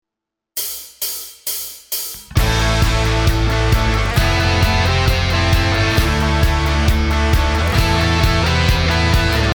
Если вернуться к теме, кроме самого звучания гитар (тембров) напрашивается еще сделать гитары пошире, например, использовать для этого даблтреки. С меня плохой гитарист, но просто для иллюстрации самой идеи, пример вступления на основе Вашего трека.